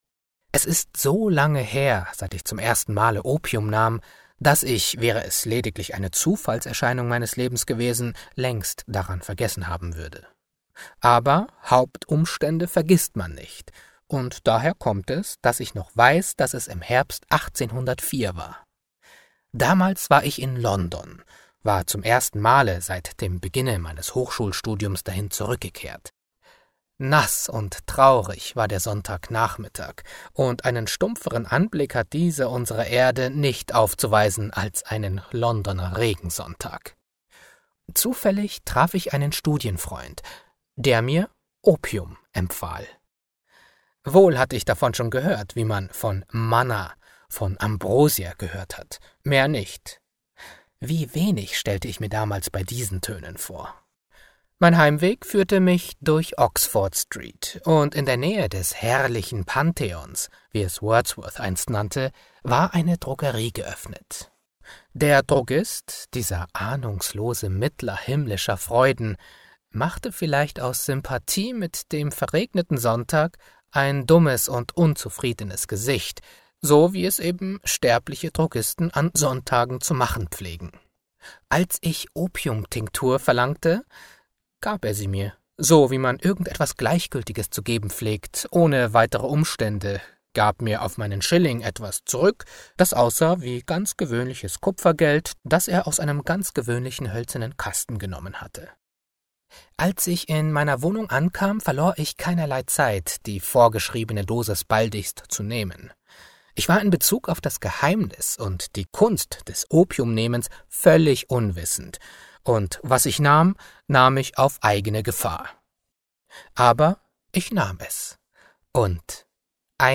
Hörbuch „Thomas De Quincey: Bekenntnisse eines englischen Opiumessers“